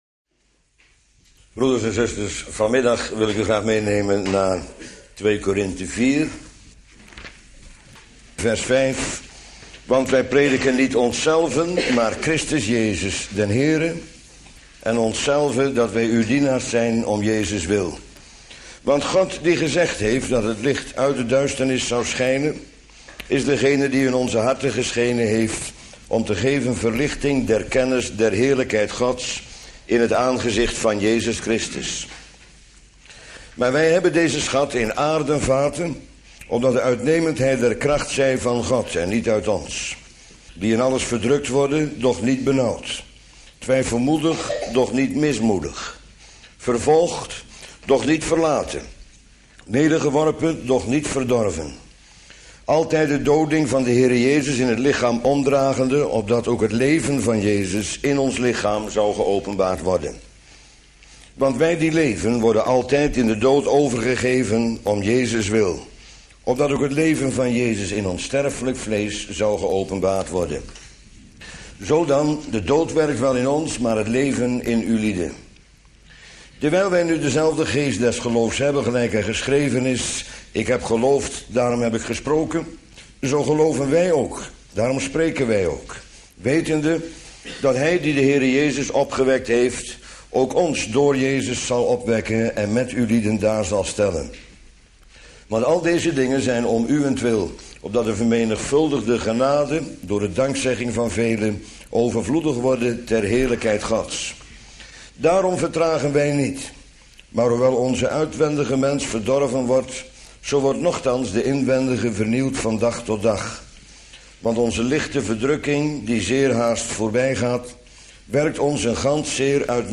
Bijbelstudie lezing